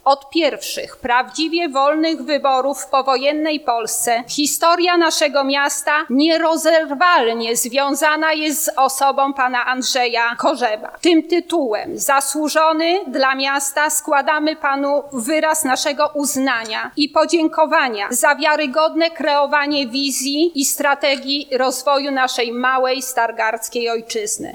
Od uhonorowania medalem Andrzeja Korzeba rozpoczęła się dwudziesta sesja rady miejskiej.
Przewodnicząca rady miejskiej – Agnieszka Ignasiak podczas wręczania nagrody zwróciła szczególną uwagę na zasługi byłego zastępcy prezydenta miasta.